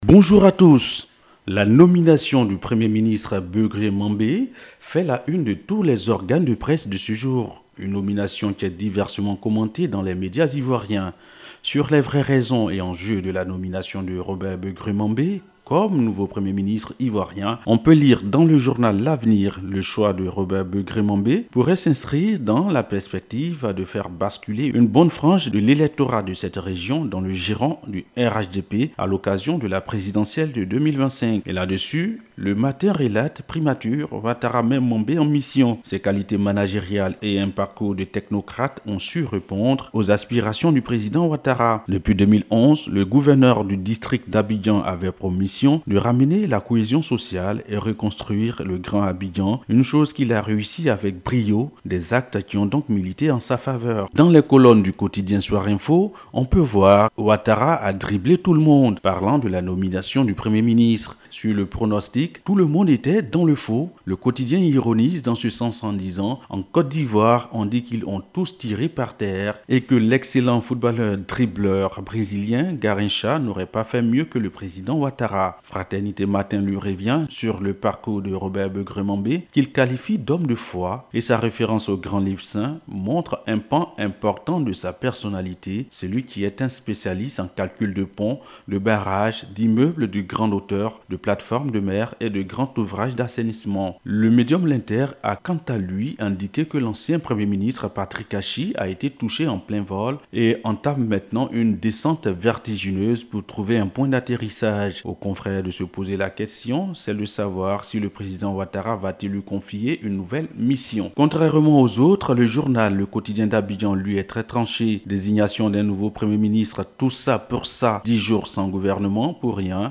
Revue de presse du 17 octobre 2023 - Site Officiel de Radio de la Paix